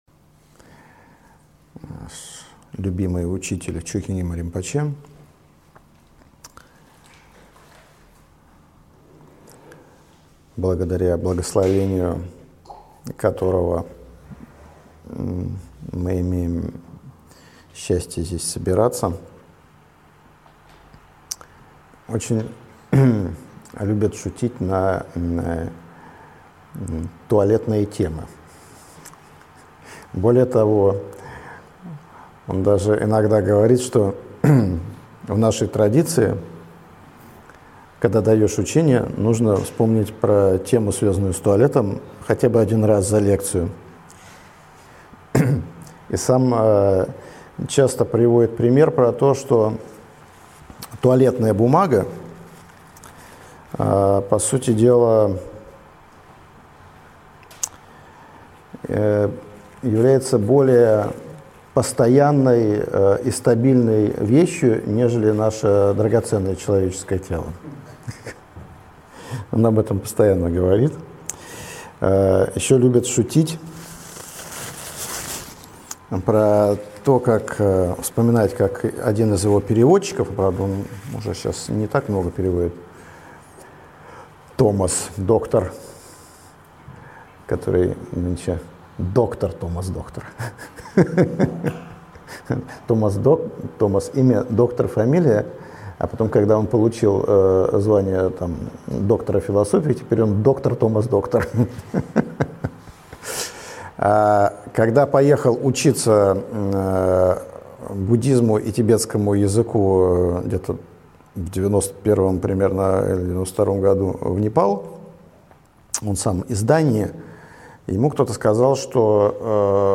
Aудиокнига Жизнь и практика в условиях турбулентности Автор Лама Сонам Дордже.